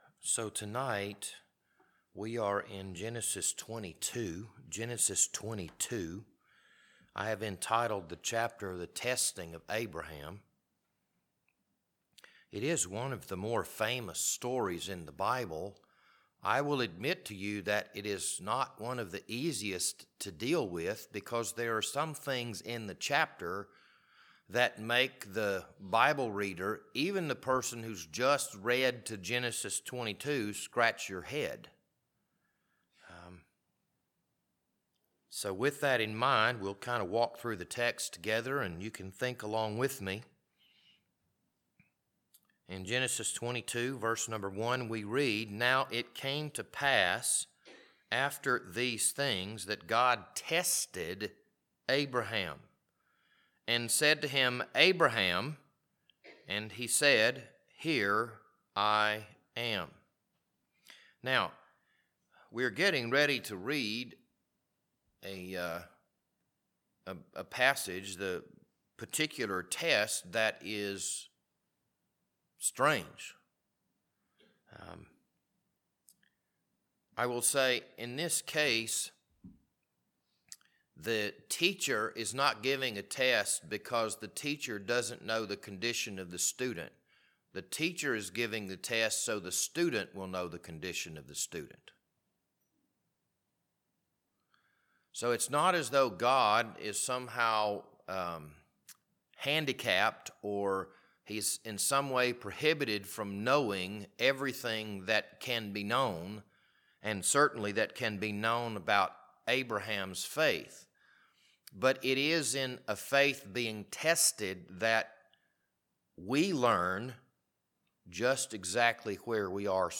This Wednesday evening Bible study was recorded on March 29th, 2023.